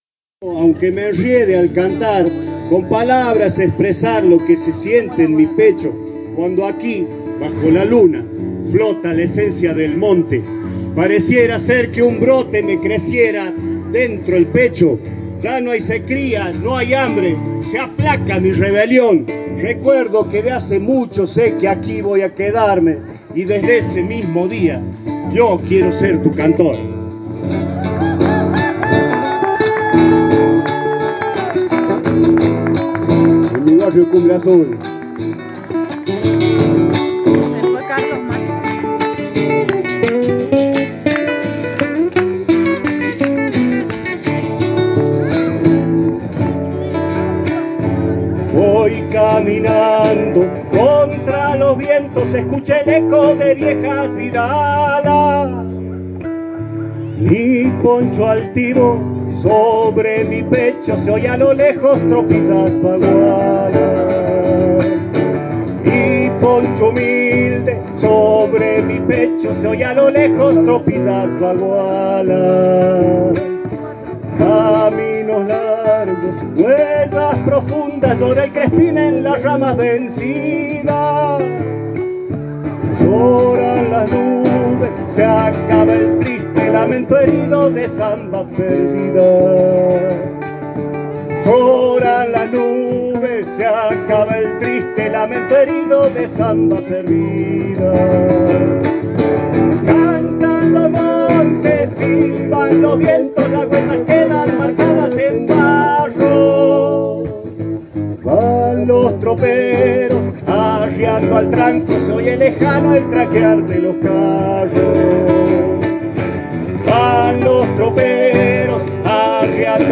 Radio del Monte estuvo presente en la fiesta que las vecinas y vecinos de Cumbre Azul organizaron para las niñas  y niños del barrio.